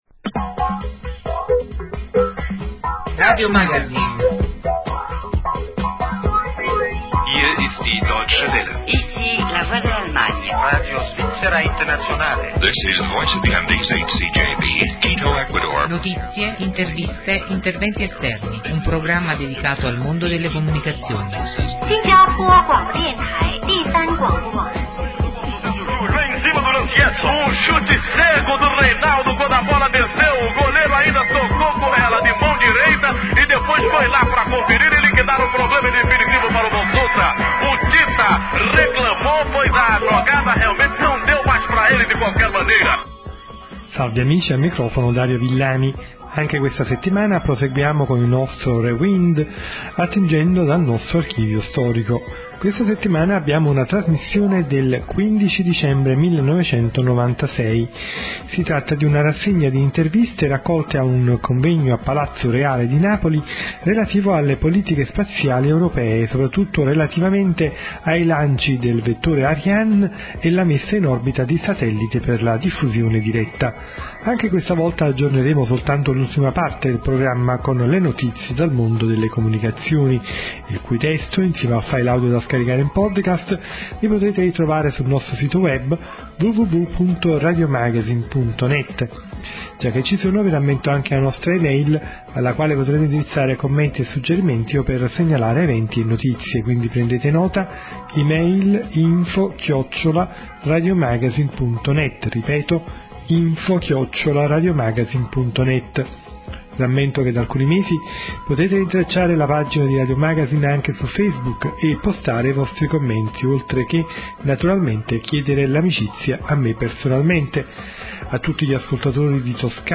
SIGLA